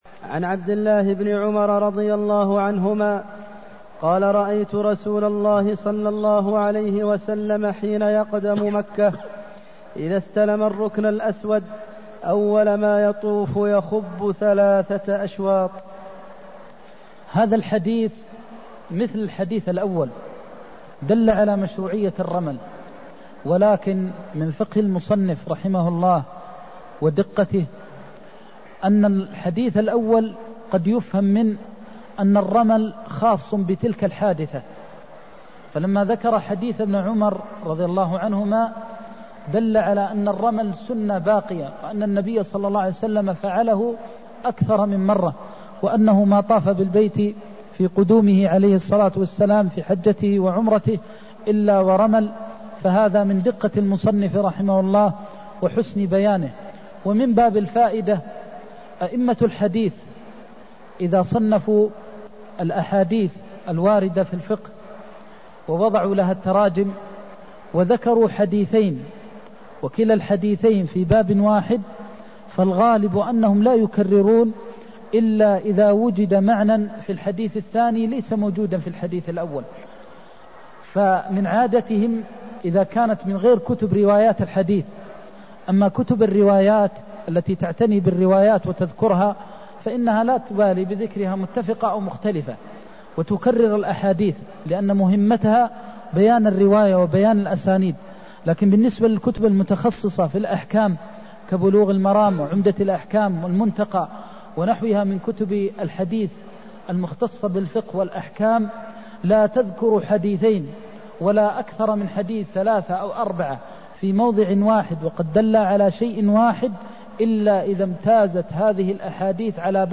المكان: المسجد النبوي الشيخ: فضيلة الشيخ د. محمد بن محمد المختار فضيلة الشيخ د. محمد بن محمد المختار استحباب الخب في الثلاثة من السبعة (217)